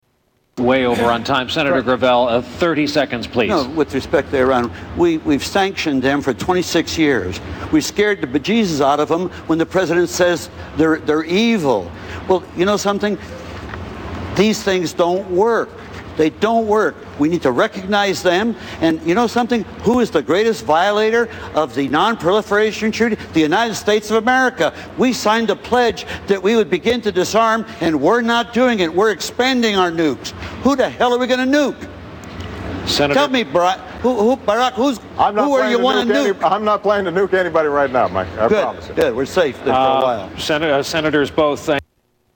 Tags: Political Mike Gravel Presidential Candidate Democratic Mike Gravel Speeches